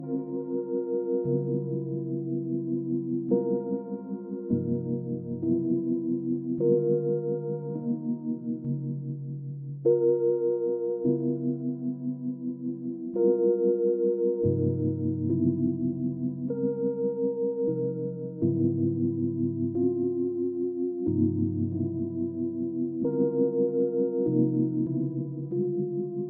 A Boombap lofi vibe sample pack with a jazzy dark ambient aura that make this collection of samples perfect to improvise over and get deep, complex and introspective tracks